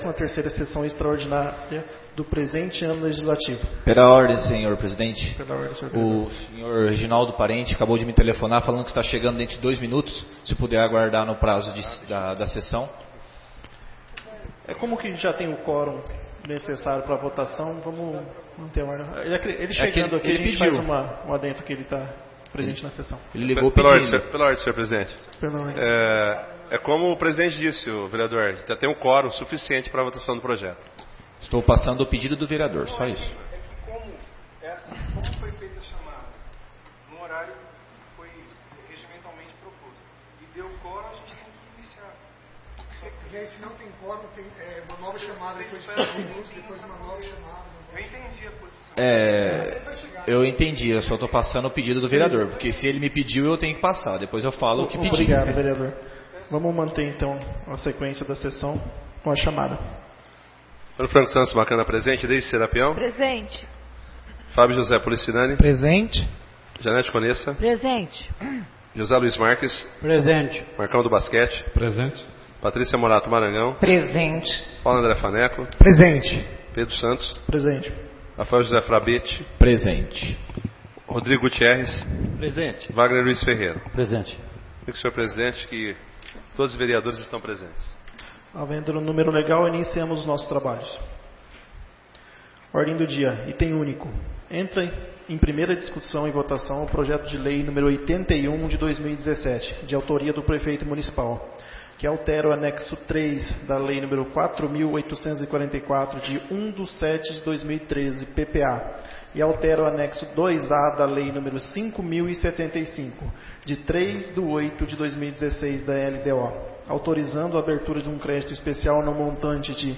13ª Sessão Extraordinária de 2017